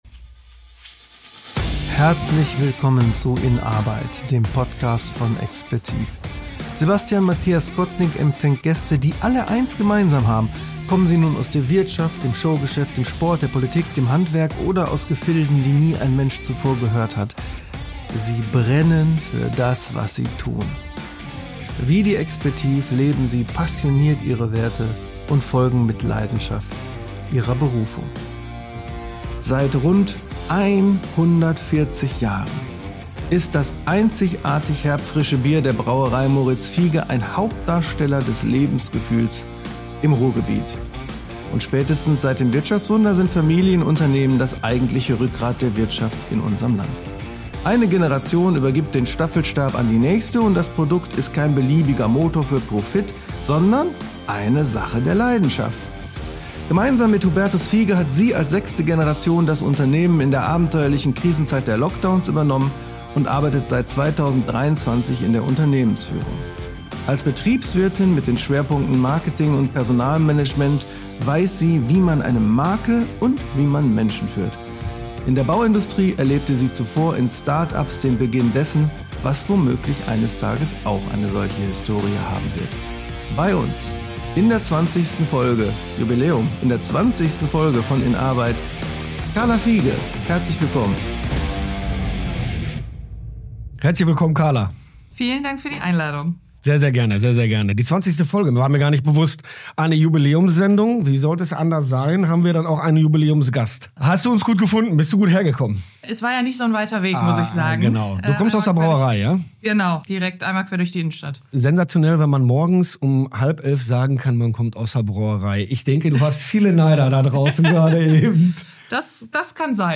Ein Gespräch über das Lernen der Braukunst in Österreich, die Verantwortung für eine Säule des Ruhrgebiets und den Segen glasklar verabredeter Verhältnisse.